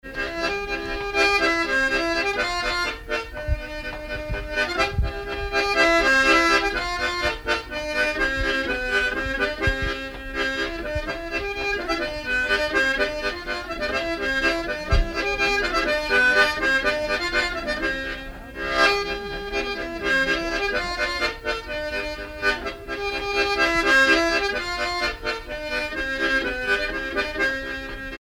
Polka
danse : polka
circonstance : bal, dancerie
Pièce musicale inédite